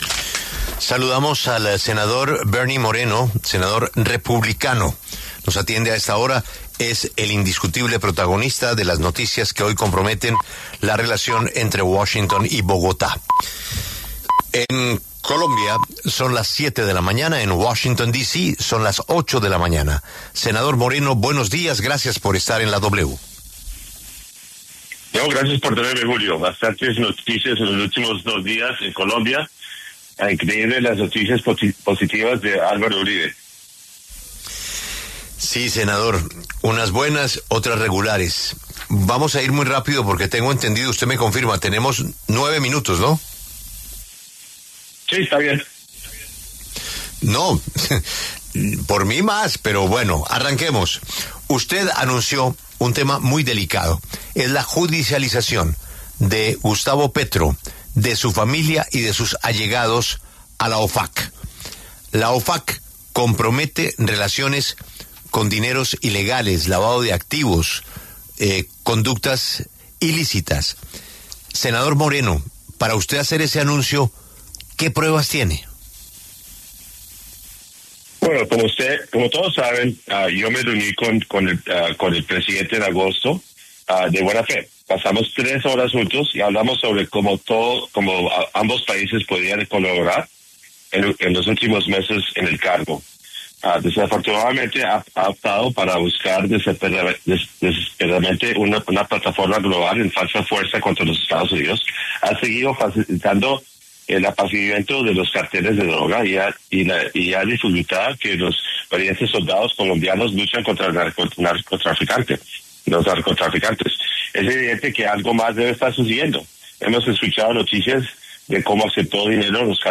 El senador republicano Bernie Moreno conversó con La W sobre la situación política de Venezuela y la permanencia de Nicolás Maduro en el poder.